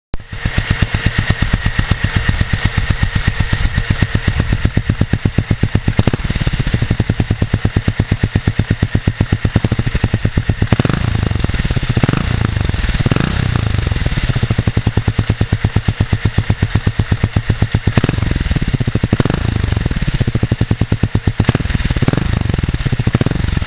Auspuff-Sounds